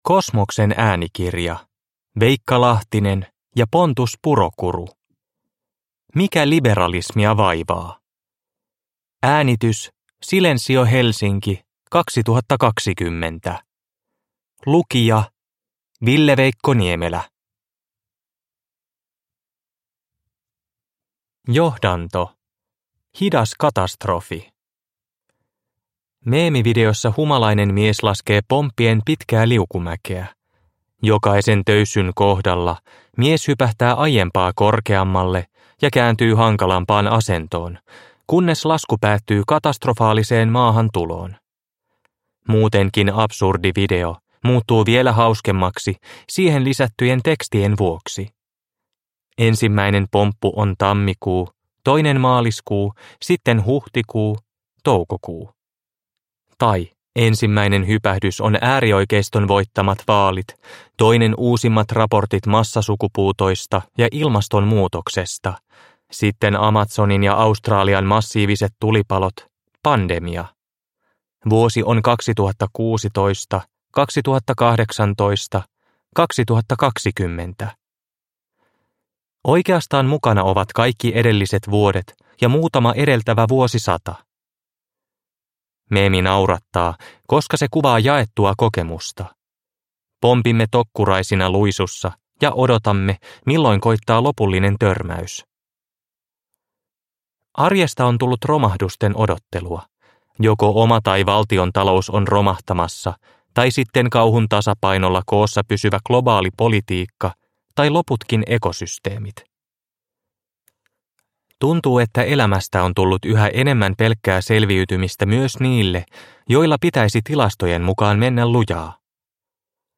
Mikä liberalismia vaivaa? (ljudbok